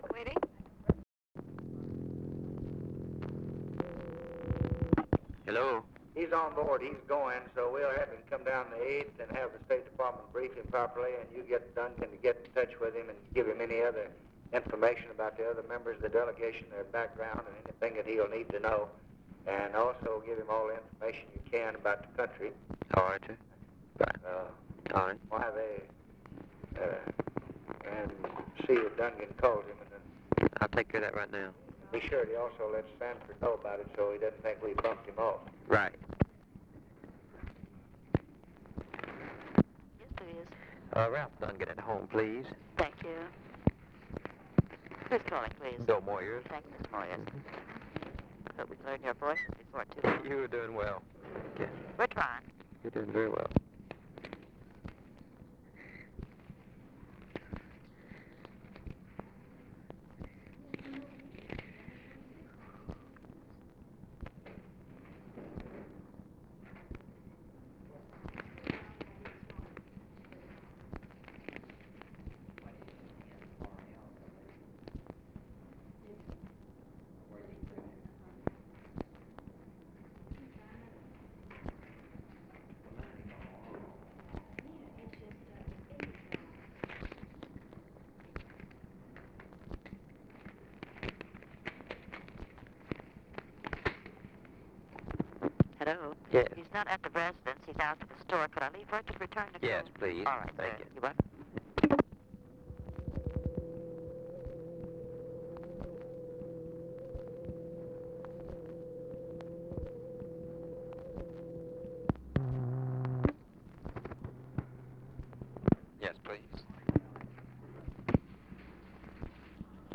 Conversation with BILL MOYERS, November 30, 1963
Secret White House Tapes